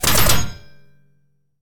CosmicRageSounds / ogg / general / combat / weapons / mgun / metal2.ogg
metal2.ogg